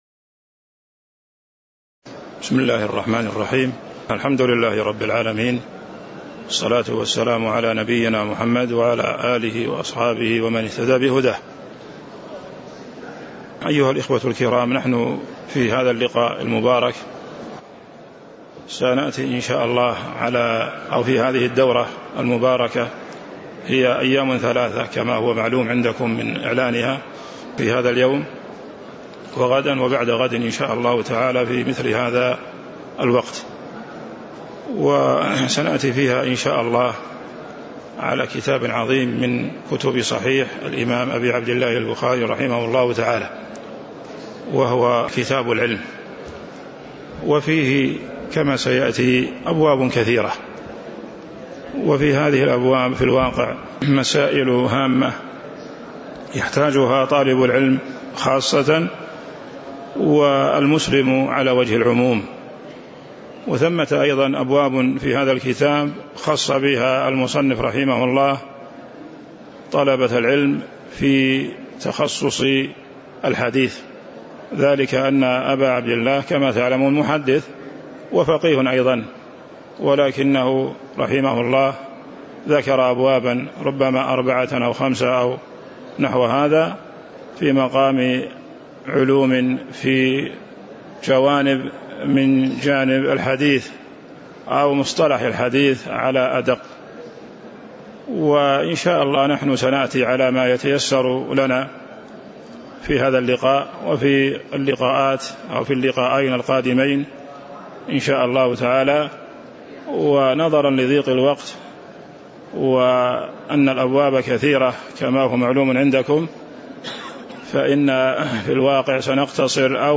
تاريخ النشر ١١ ربيع الثاني ١٤٤٣ هـ المكان: المسجد النبوي الشيخ